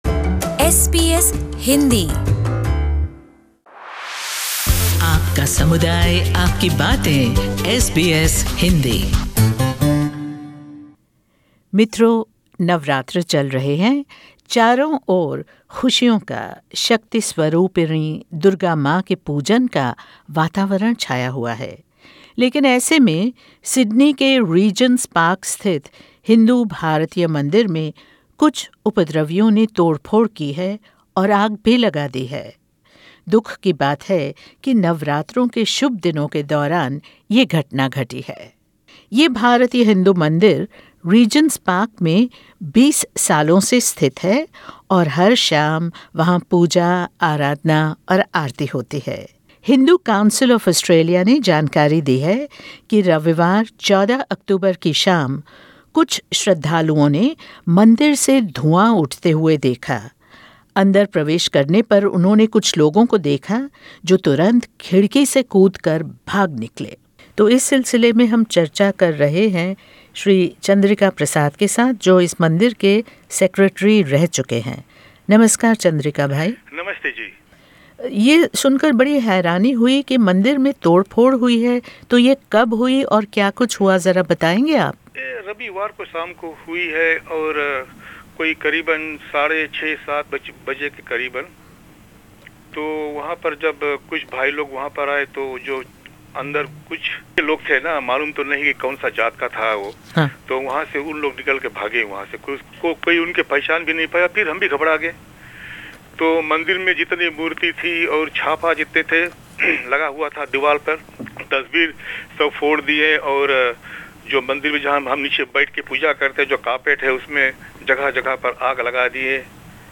During this auspicious festive season, the Bharatiya Hindu temple in Regent's Park Sydney has been vandalised. Images and idols have been broken but the spirit and faith of the community are not shattered. Tune in to hear the full report.